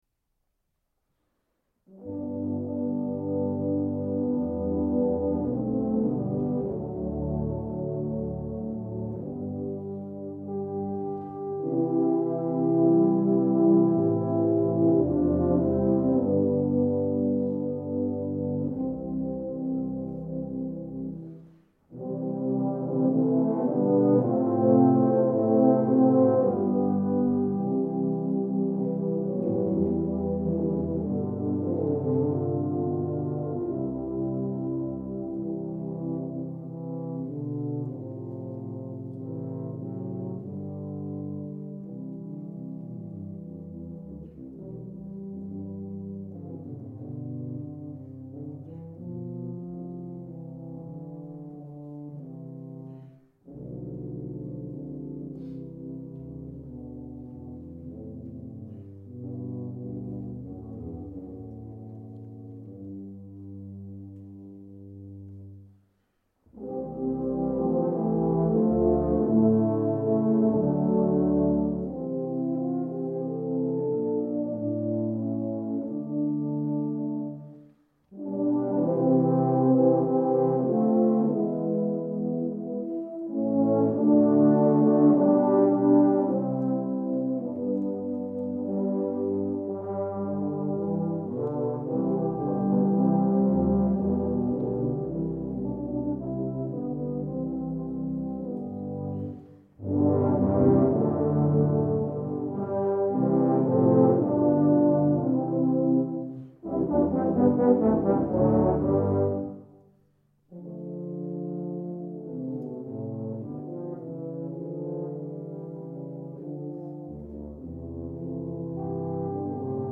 Voicing: Tuba Ensemble